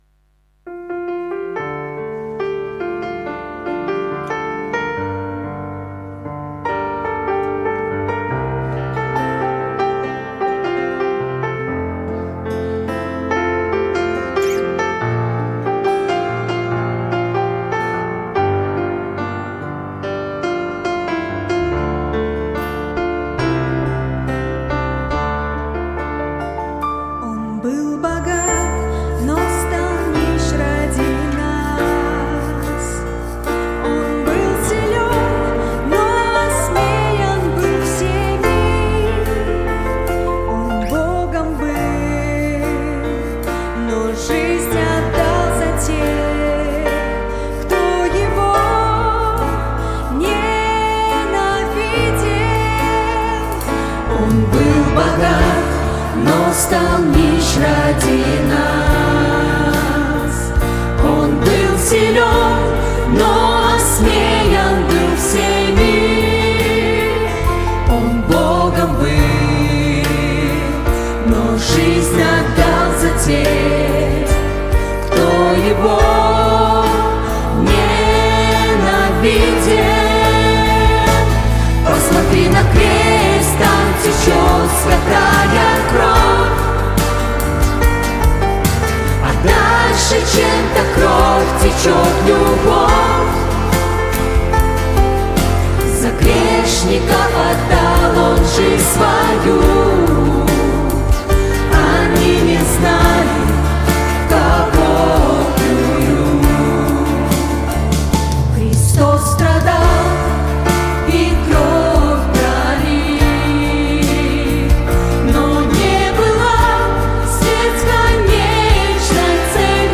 Псалом